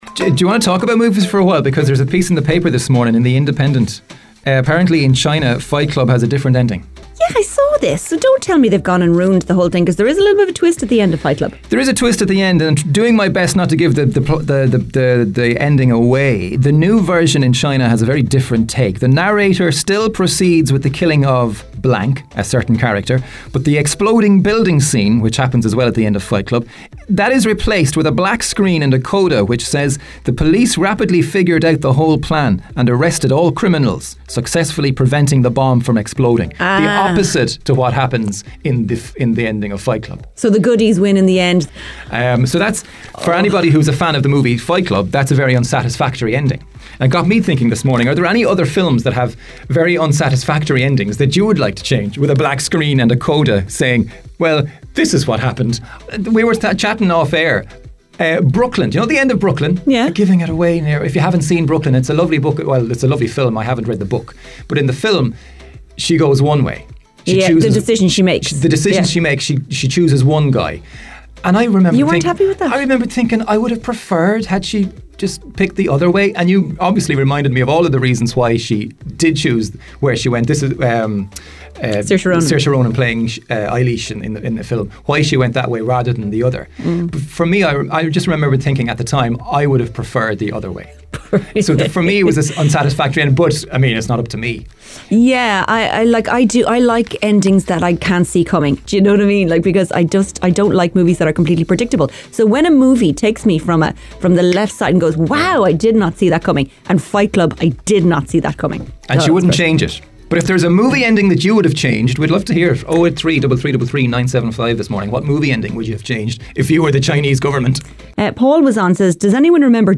Replies included famous titles such as Titanic and Dirty Dancing!